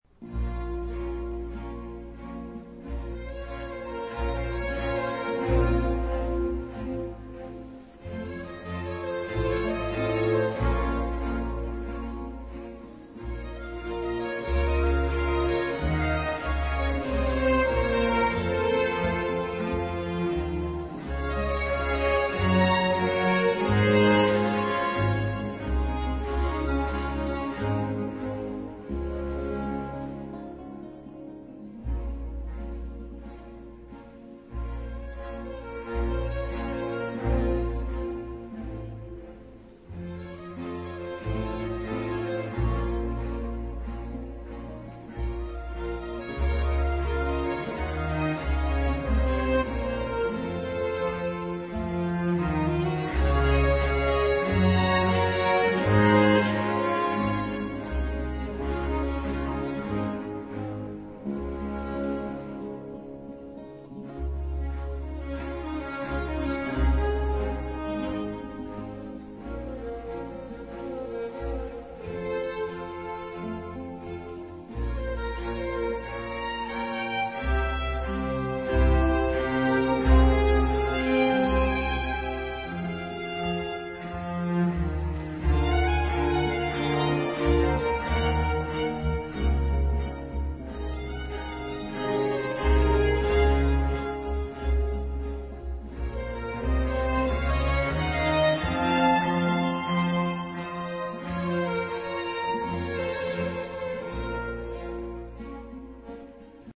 詮釋以極大熱情、充滿幻想和自由為特徵。
如此以來，形成很強烈的雙琴特色。
五弦大提琴接近中提琴的音域，以之來替代小提琴，改變了聲響的原味。